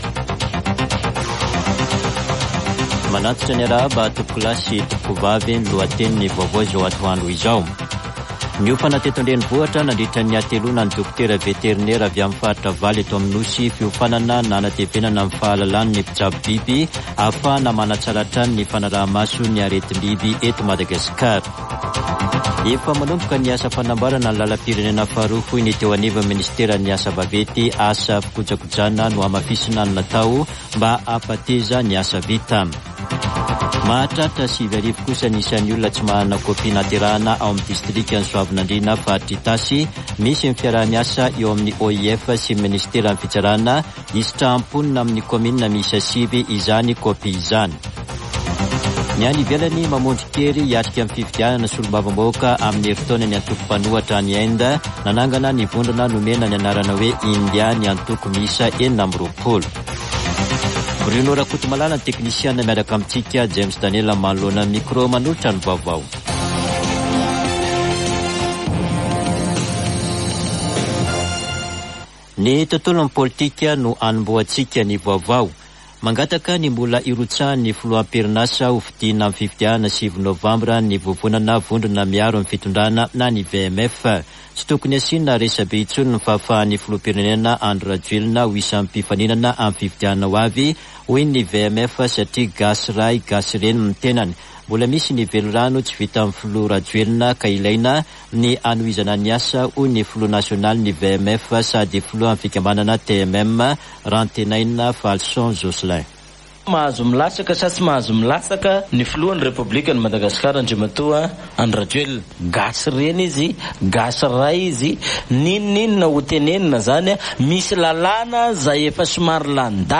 [Vaovao antoandro] Alakamisy 20 jolay 2023